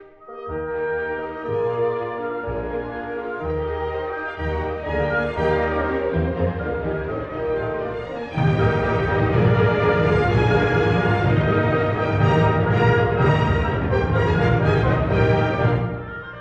↑古い録音のため聴きづらいかもしれません！（以下同様）
Finale: Allegro molto
～フィナーレ：とても快速に～
とてもテンポの速い、変奏曲風な楽章です。
終わりにやってくるコーダもとても大きなスケールのものとなっています。